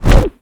pgs/Assets/Audio/Magic_Spells/casting_charge_whoosh_buildup5.wav
A=PCM,F=96000,W=32,M=stereo
casting_charge_whoosh_buildup5.wav